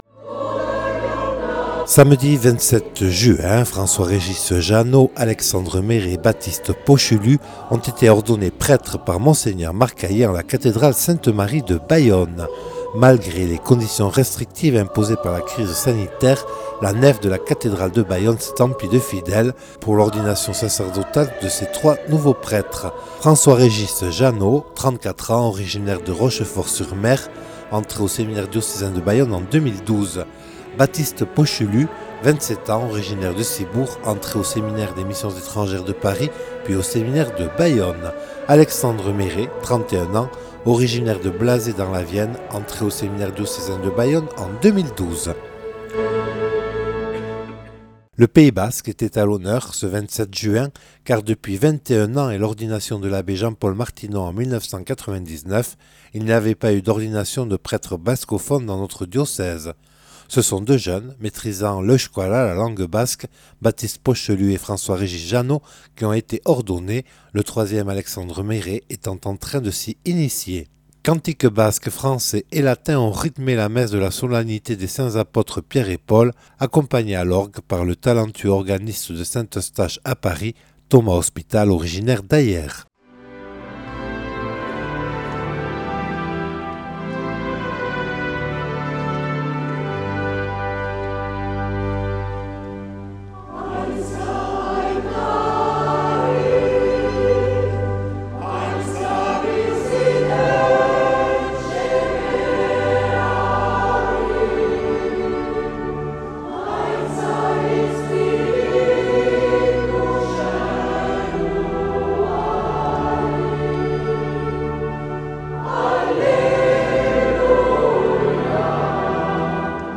Retour sur les ordinations sacerdotales du 27 juin 2020 en la cathédrale sainte-Marie de Bayonne.
Interviews et reportages